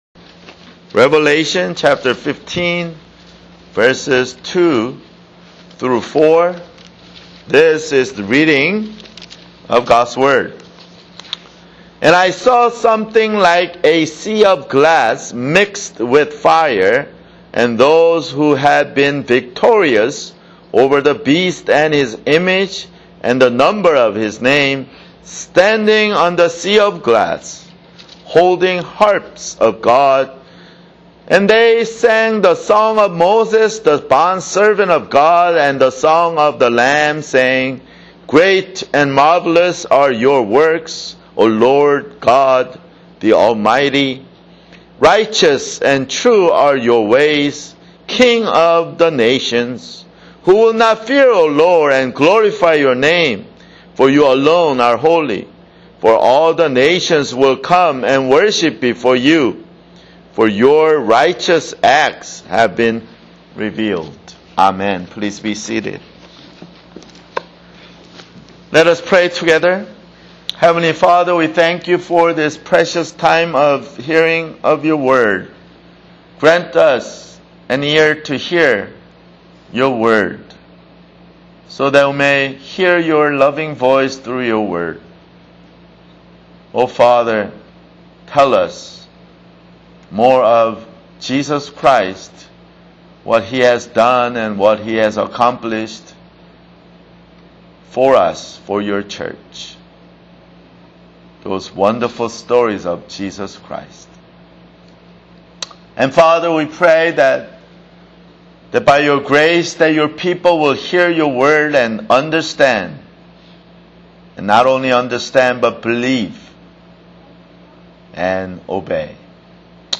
[Sermon] Revelation (56)